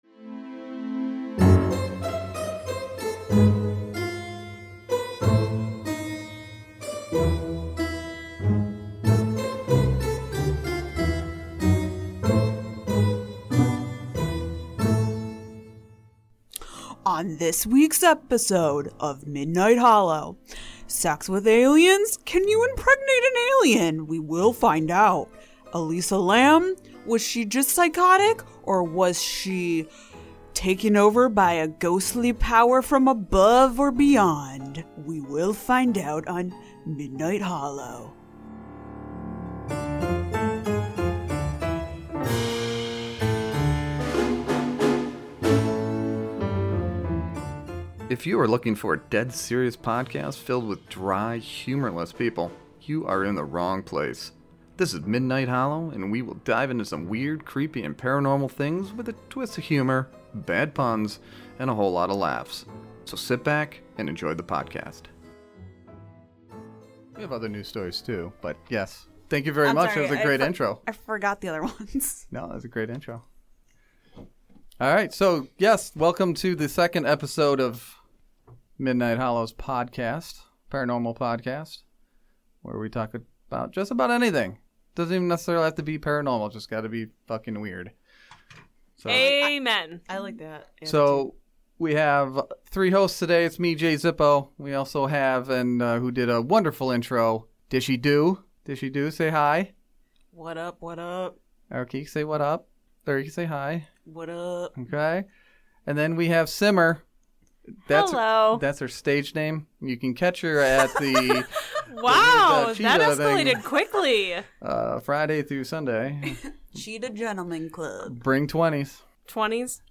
Our topic of conversation is Elyse Lam. We discuss her disappearance, death and odd behavior leading up to it. We fire off some theories of our own that might make you rethink some of the facts.